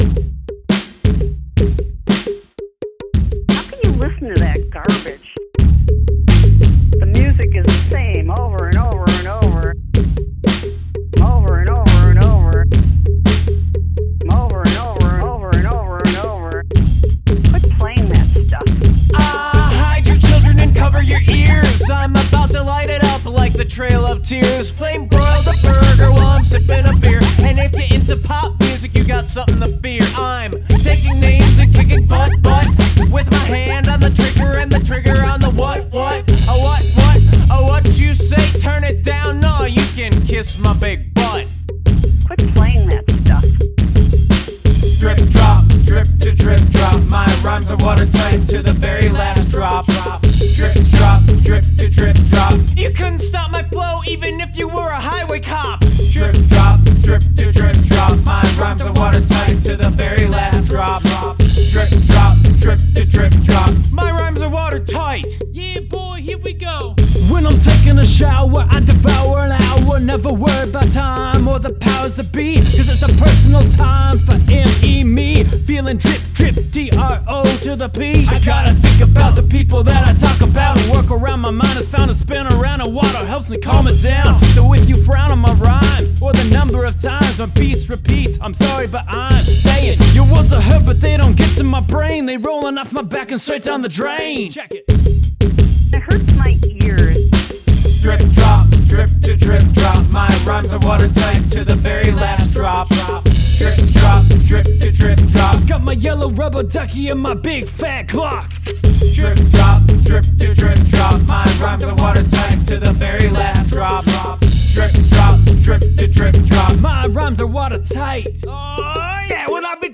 Fun Beastie Boys style jam.